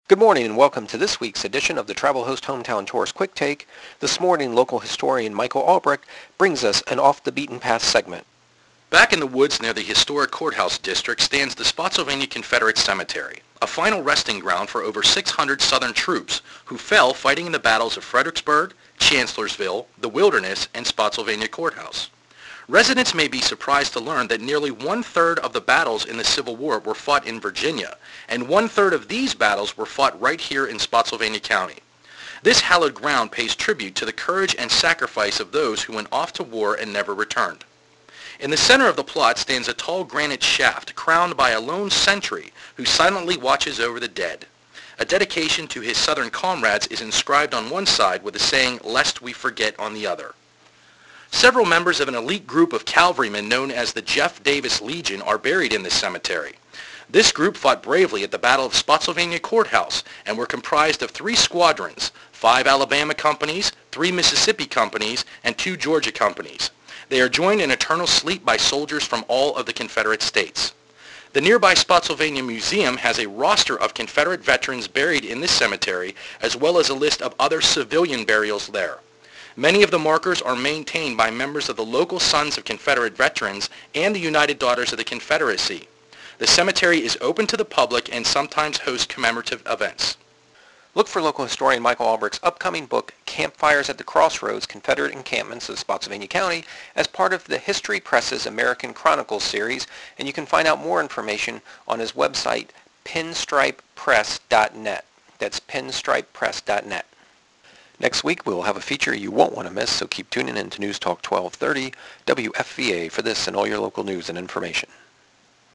My latest radio spot for AM 1230: